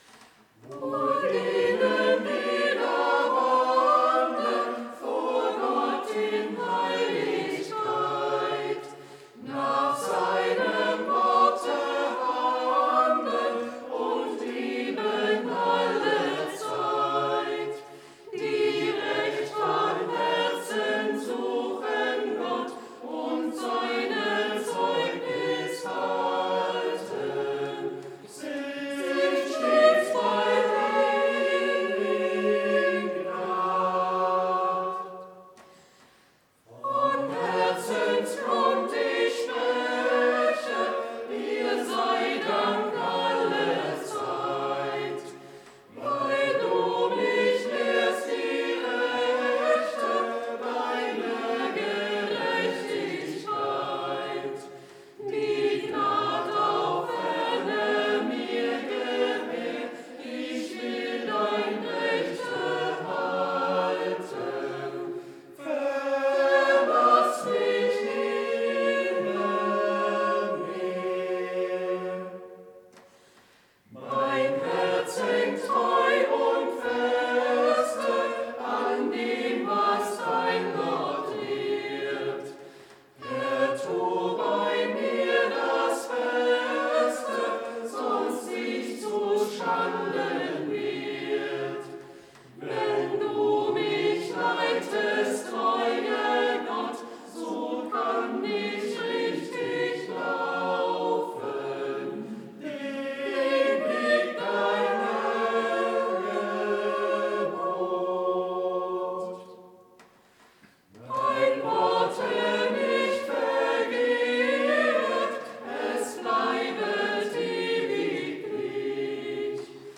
Gottesdienst am 23.02.2025
Wohl denen, die da wandeln... Chor der Ev.-Luth. St. Johannesgemeinde Zwickau-Planitz